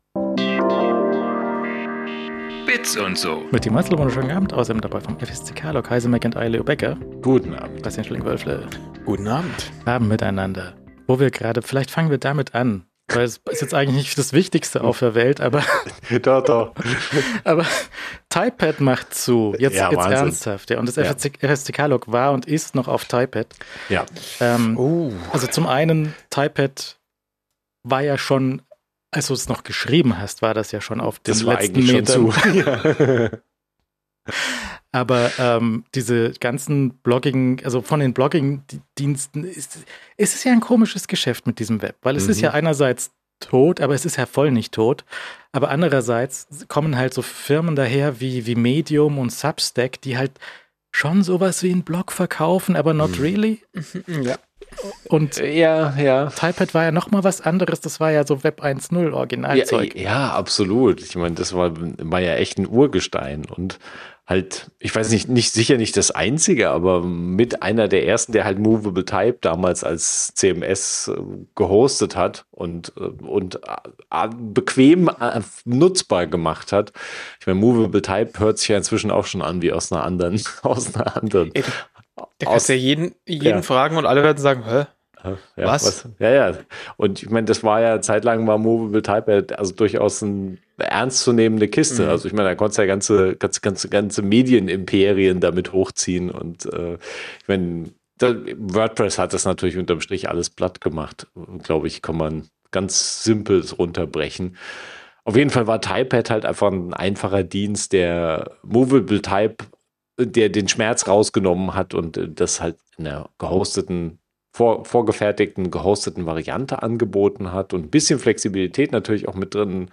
Die wöchentliche Talkrunde rund um Apple, Mac, iPod + iPhone, Gadgets und so. Fast live aus München.